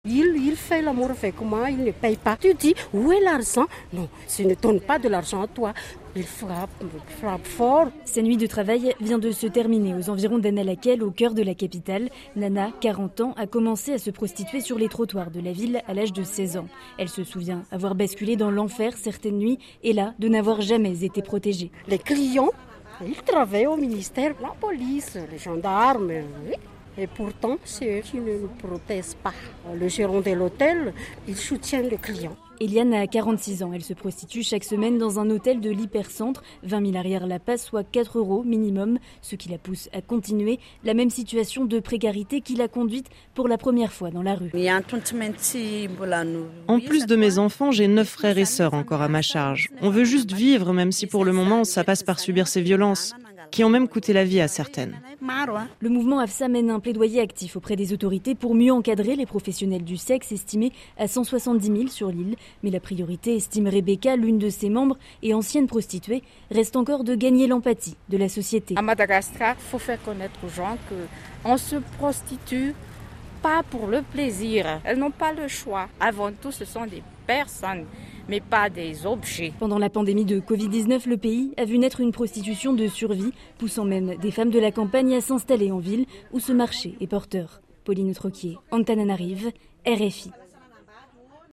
madagascar-reportage-rfi.mp3